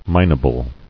[min·a·ble]